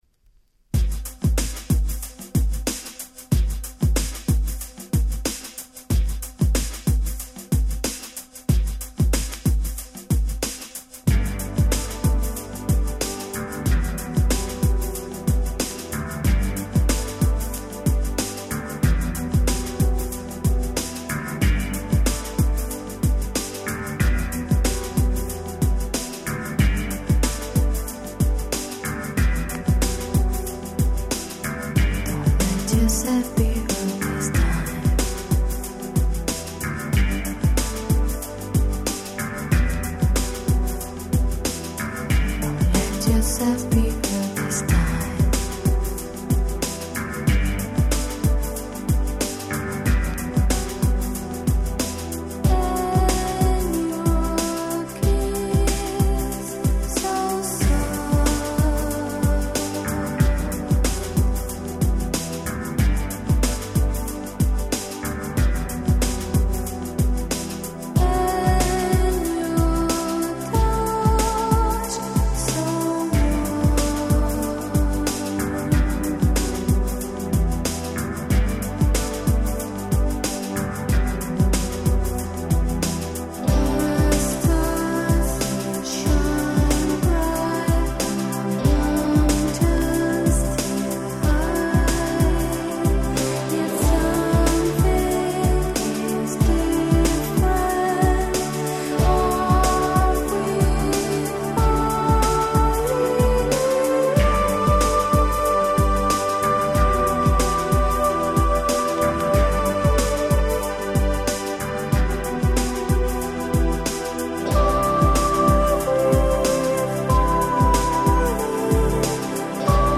【Media】Vinyl 12'' Single
91' 人気Ground Beat !!